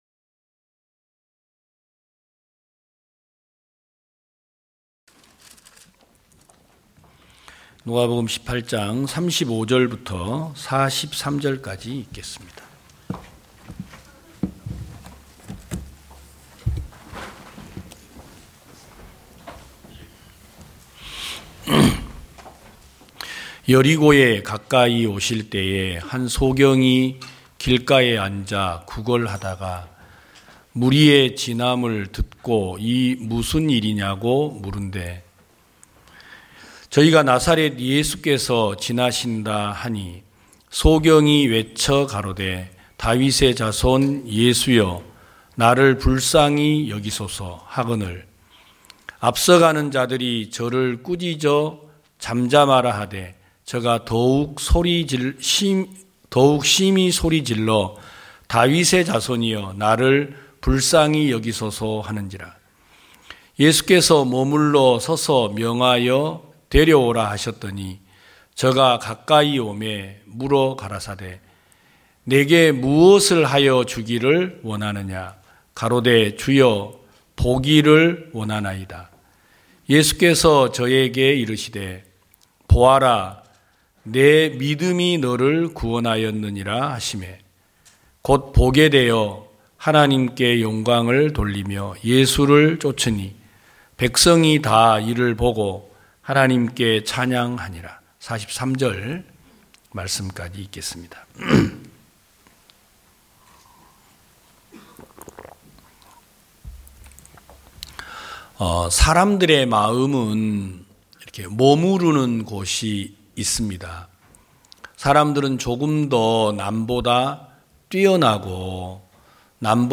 2022년 12월 11일 기쁜소식부산대연교회 주일오전예배
성도들이 모두 교회에 모여 말씀을 듣는 주일 예배의 설교는, 한 주간 우리 마음을 채웠던 생각을 내려두고 하나님의 말씀으로 가득 채우는 시간입니다.